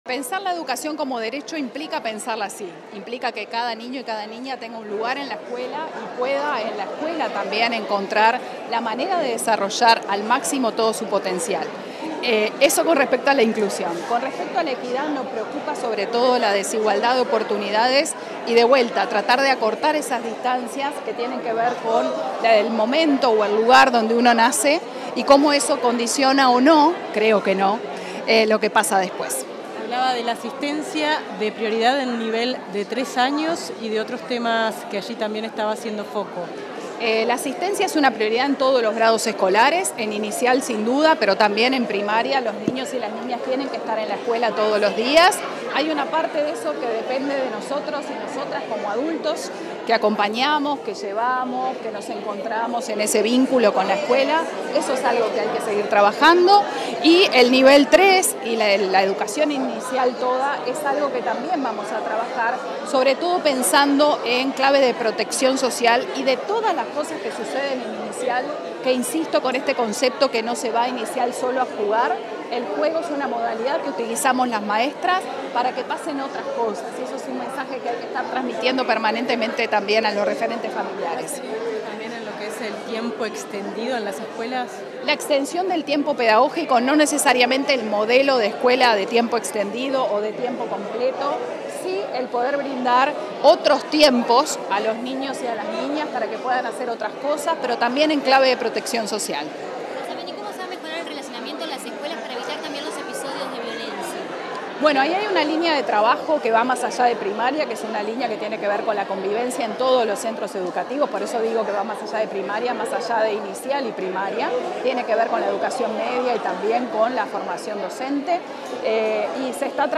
Declaraciones de la directora general de Educación Inicial y Primaria, Gabriela Salsamendi
Declaraciones de la directora general de Educación Inicial y Primaria, Gabriela Salsamendi 28/03/2025 Compartir Facebook X Copiar enlace WhatsApp LinkedIn Tras asumir al frente de la Dirección General de Educación Inicial y Primaria, este 28 de marzo, la nueva titular, Gabriela Salsamendi, realizó declaraciones a la prensa.